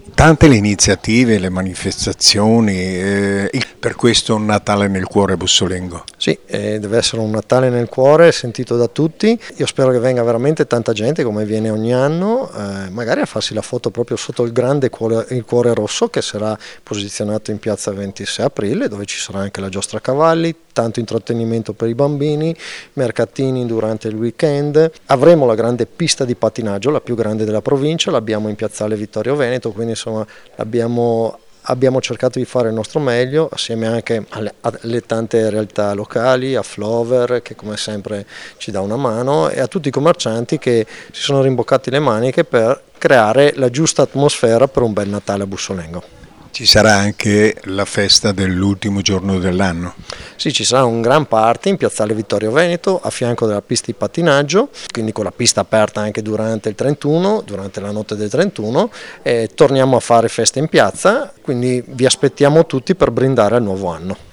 Massimo Girelli, vice sindaco e assessore alle manifestazione di Bussolengo